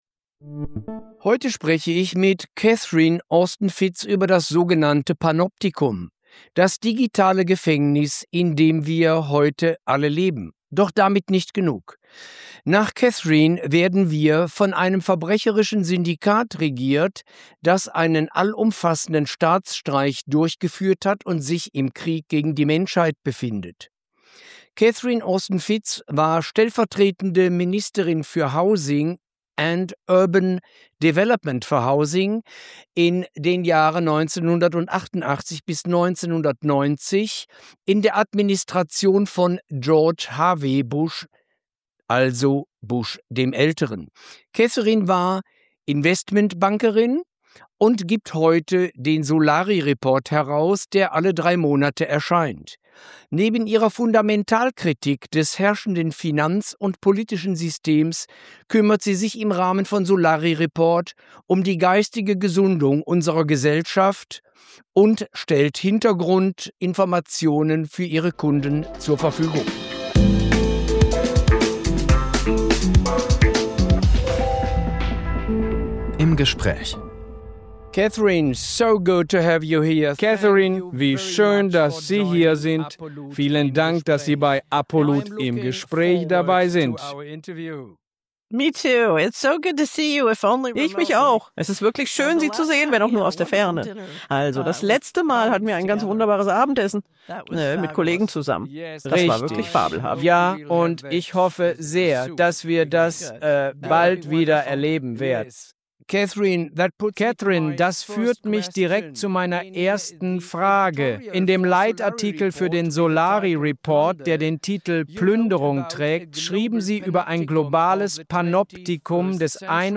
im-gespraech-catherine-austin-fitts-apolut.mp3